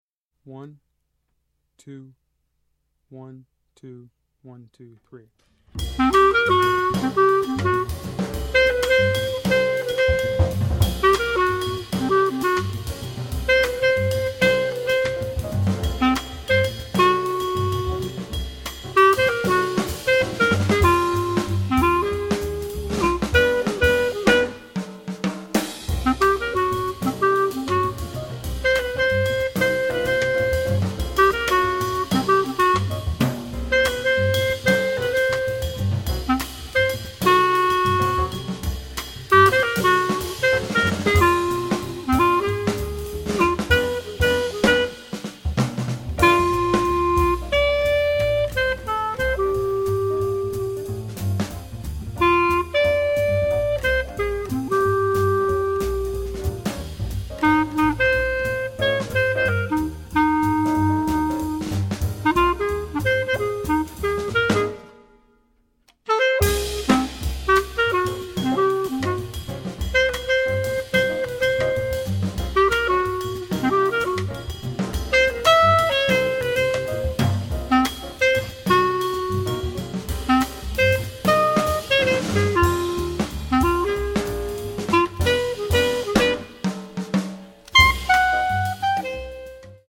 Voicing: Clarinet